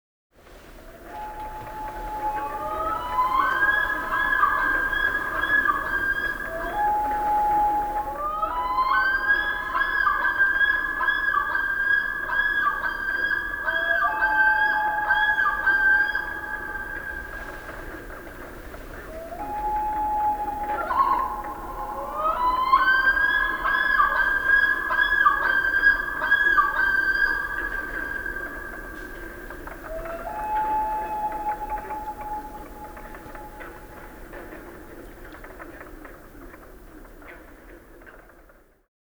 canadian-loon.wav